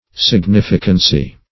Significance \Sig*nif"i*cance\, Significancy \Sig*nif"i*can*cy\,
significancy.mp3